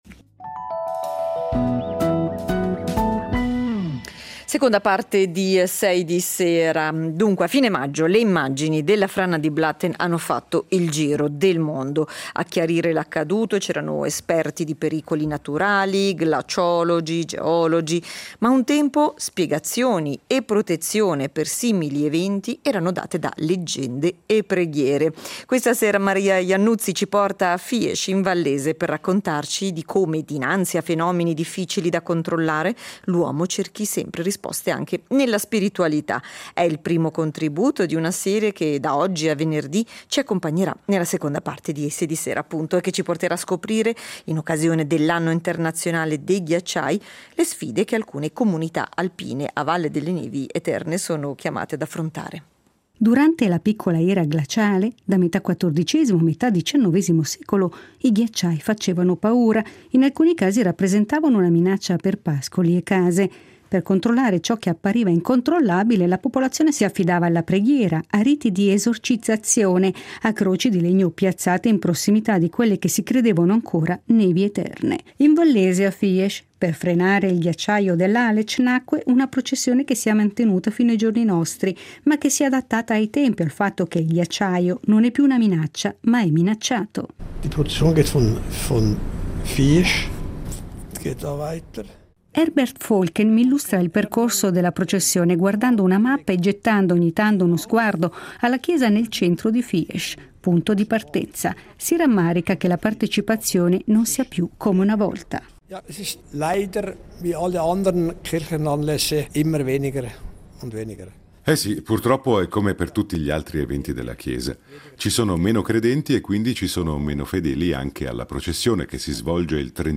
Il servizio radiofonico